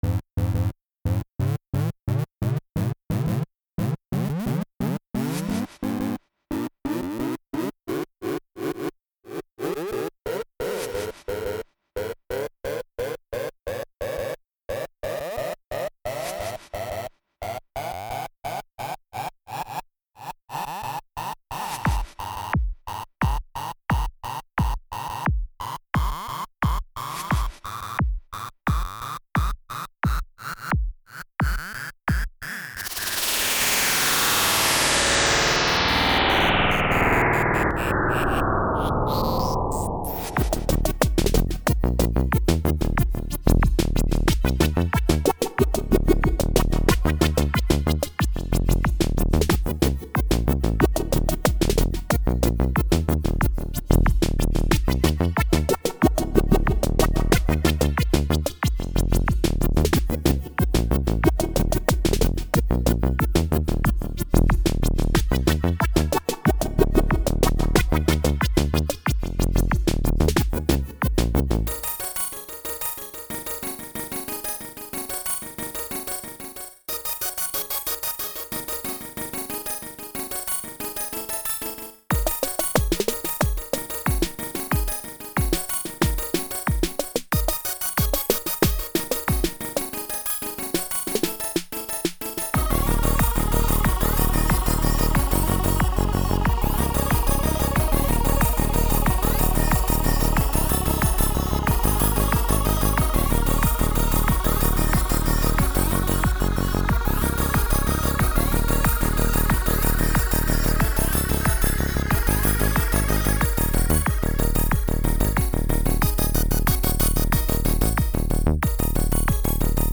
I don't really consider this chip music but what the fuck.